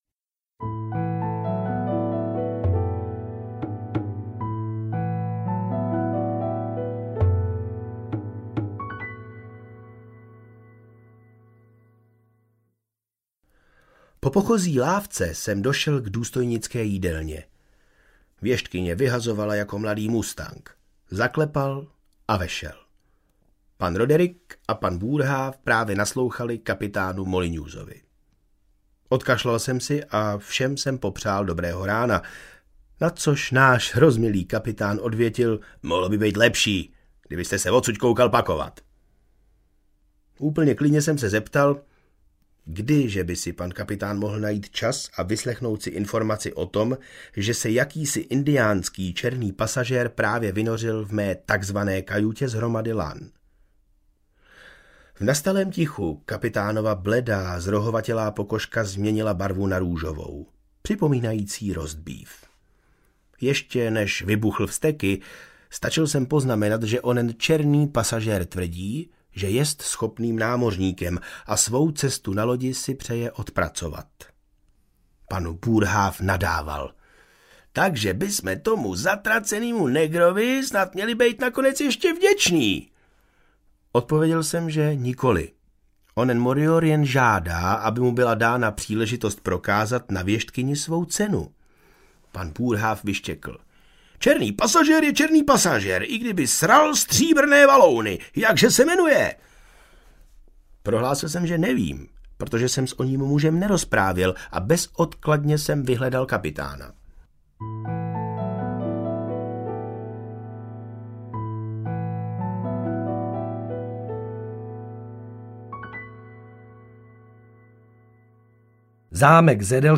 Atlas Mraků audiokniha
Ukázka z knihy